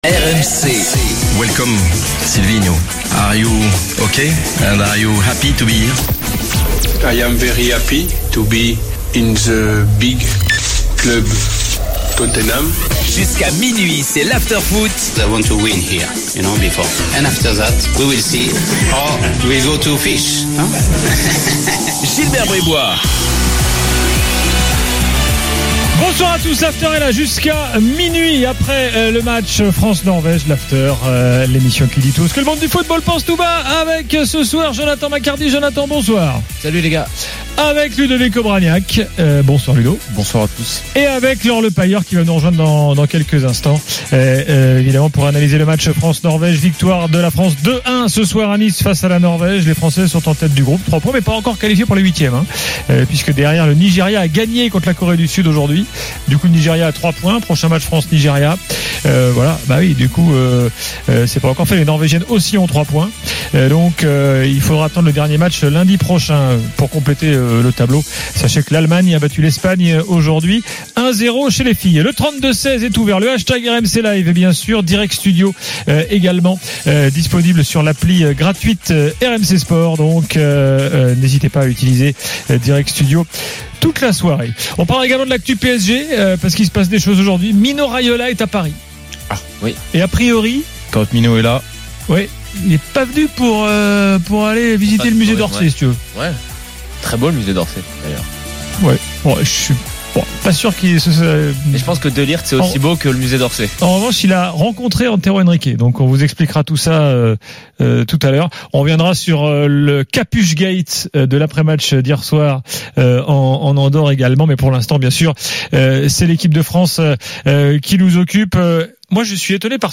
le micro de RMC est à vous !